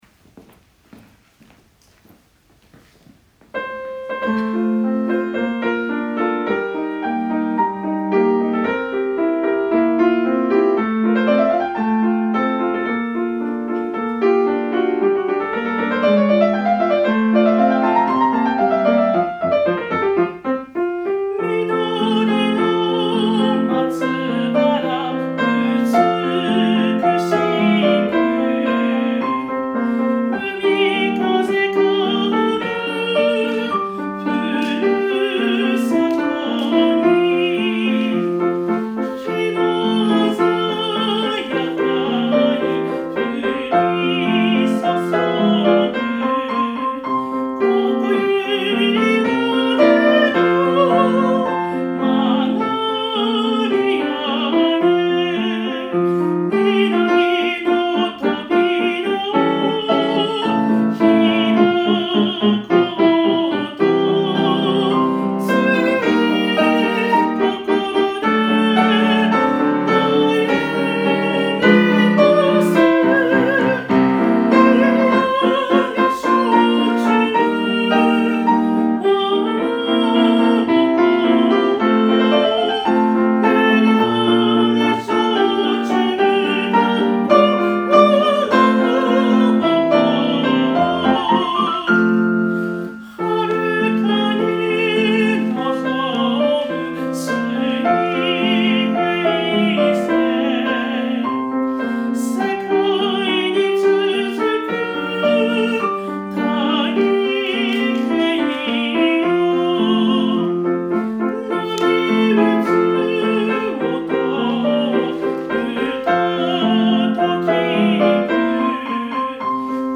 校歌の音源です。
作曲　今井　邦男